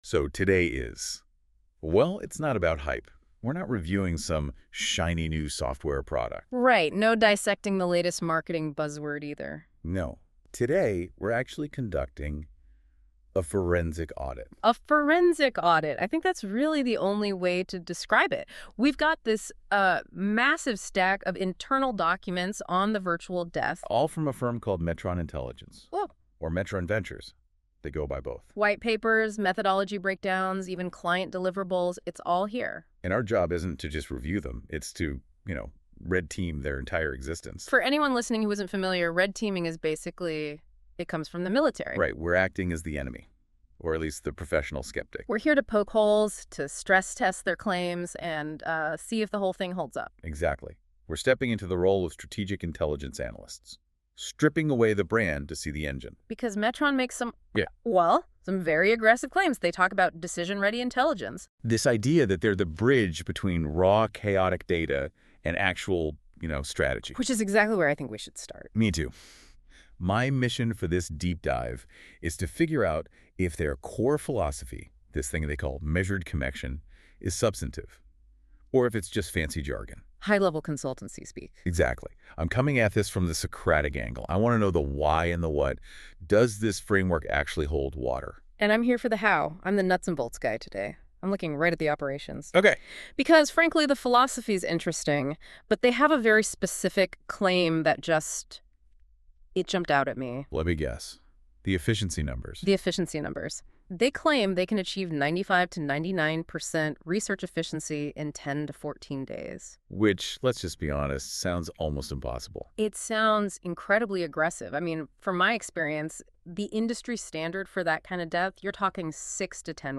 Listen to the Metron Intelligence Brief: Strategic R&D insights and decision-ready intelligence. 20-40 minute podcast-style narration optimized for executive consumption.